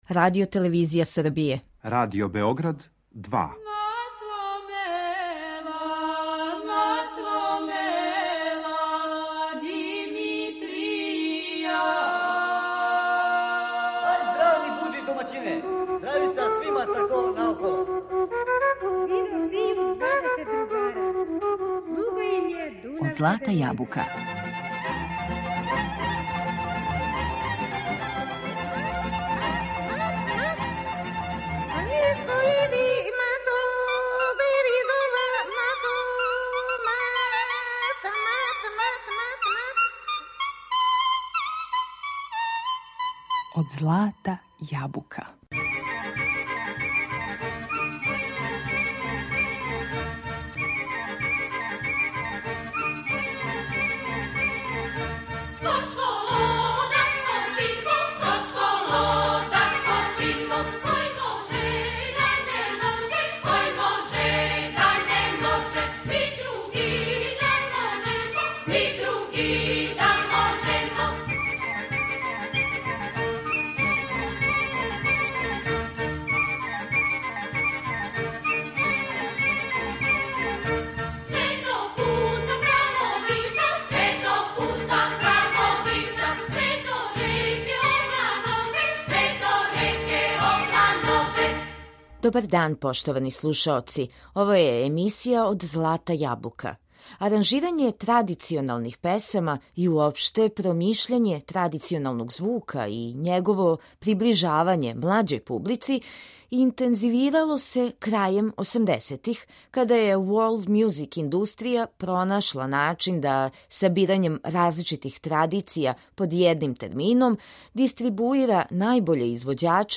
У фокусу овог издања је третман традиционалне песме и њено обликовање за потребе програма Радио Београда. У питању су најинтересантнији аранжмани Ђорђа Караклајића и других аутора који су писали за женски вокални ансамбл „Шумадија", настали у другој половини 20. века.